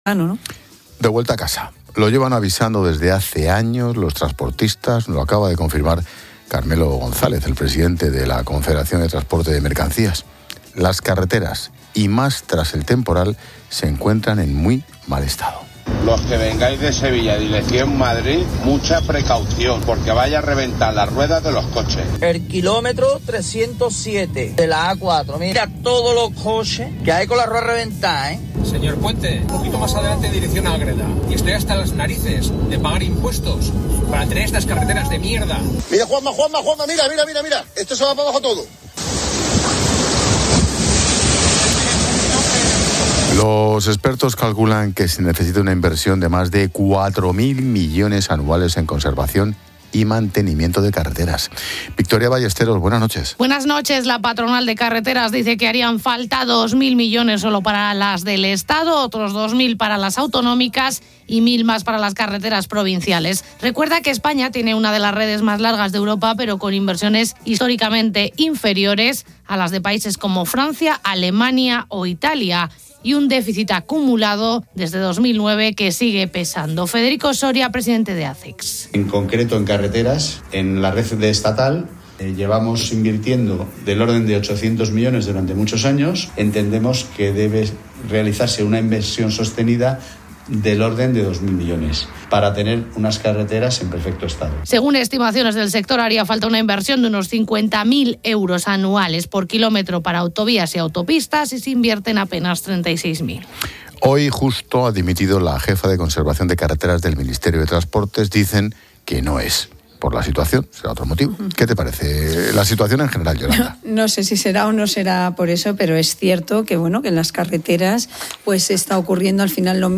Con Ángel Expósito